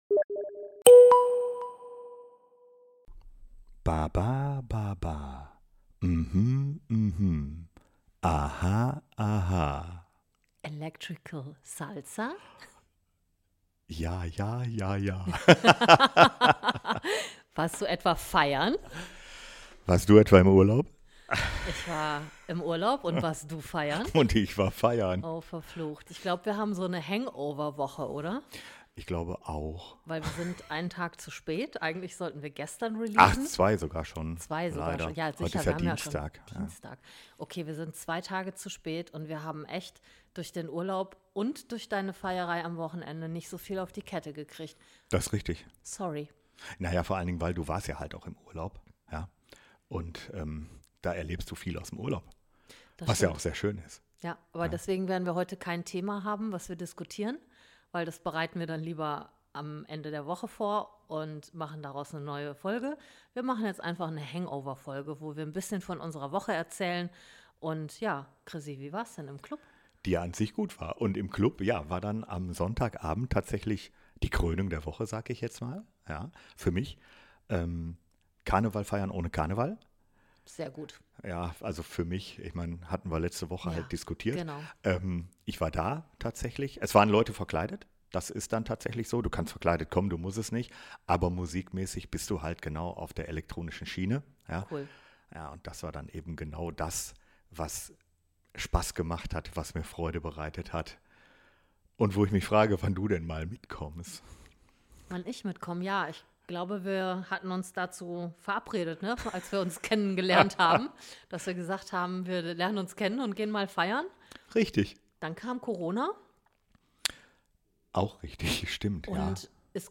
Getalkt wird off-topic und ohne Thema.